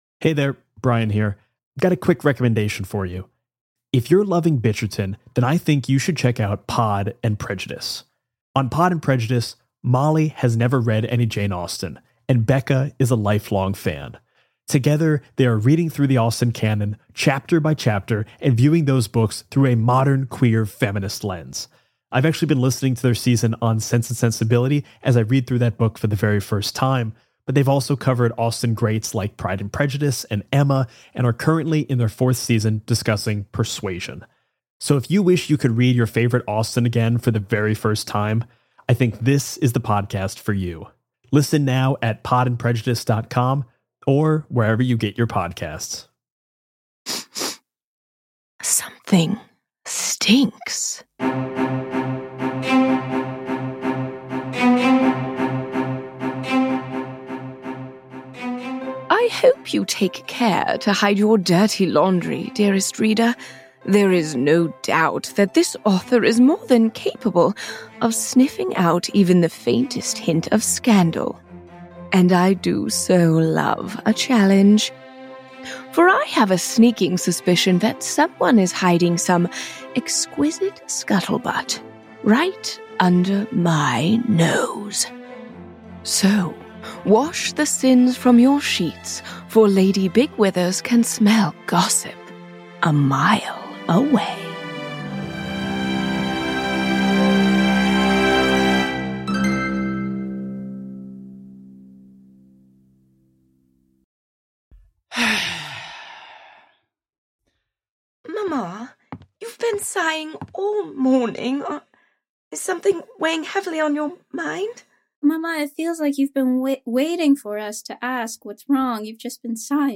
An improvised comedy podcast satirizing the world of Jane Austen that tells the tale of a wealthy family's scandals and shenanigans.